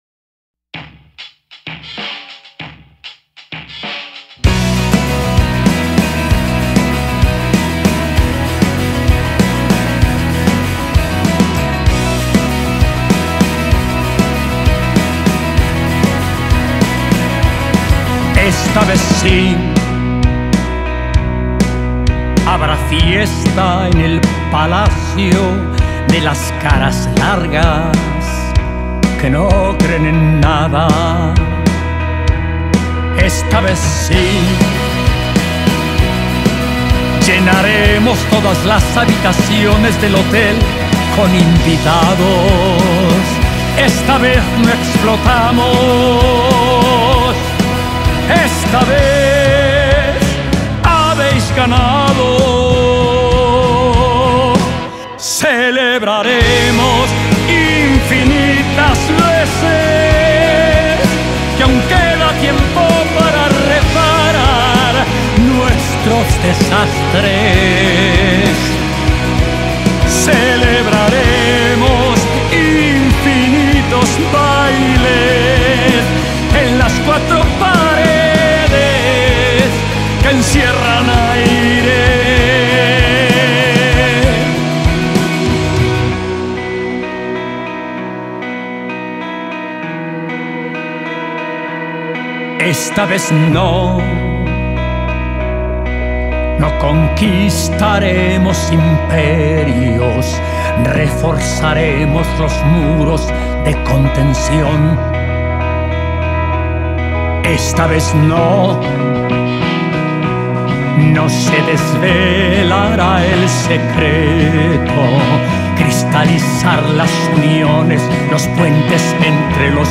Entrevista a Raphael y Joana Jiménez
Hoy con Raphael y Joana Jiménez como invitados